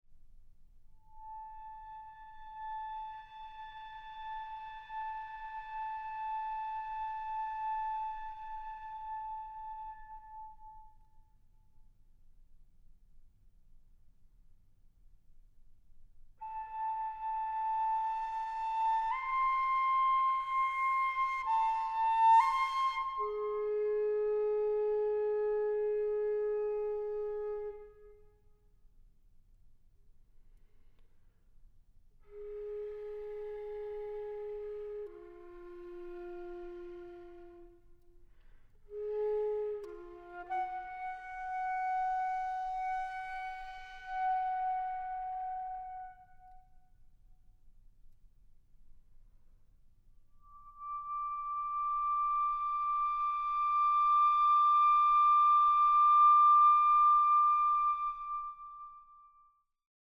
Recording: Deutschlandfunk Kammermusiksaal, Köln, 2023 + 2024